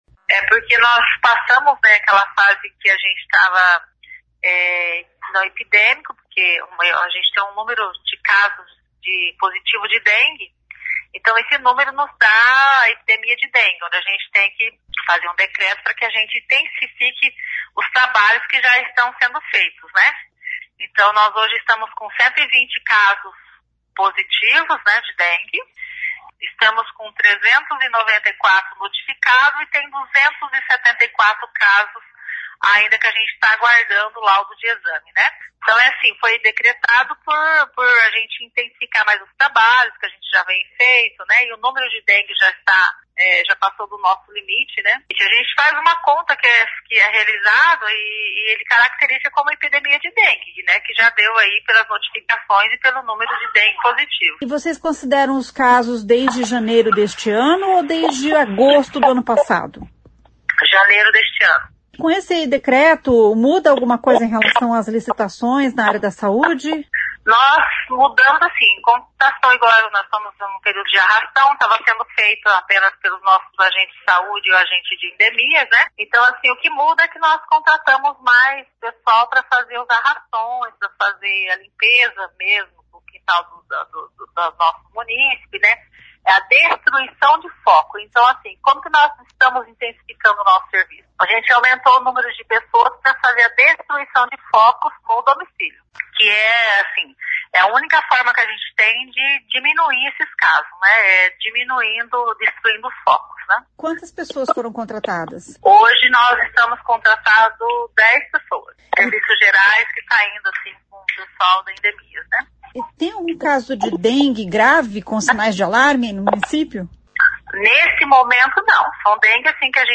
Ouça o que diz a secretária de Saúde: